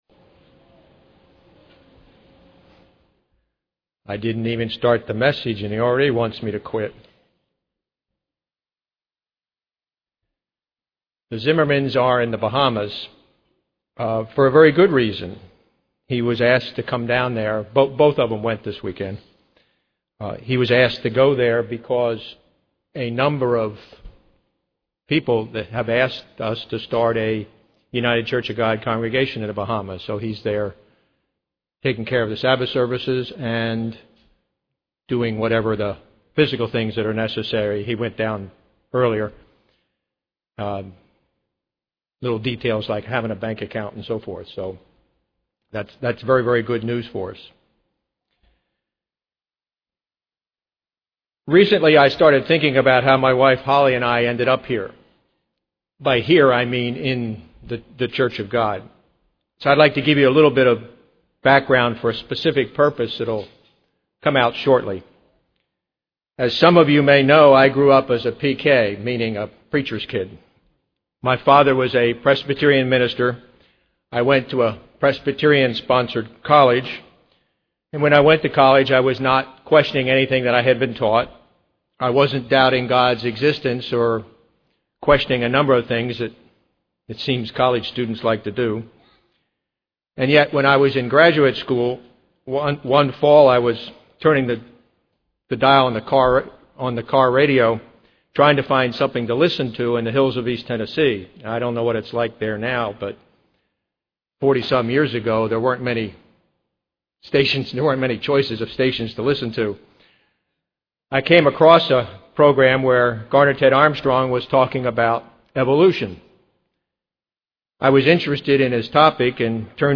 Given in Ft. Lauderdale, FL
UCG Sermon Studying the bible?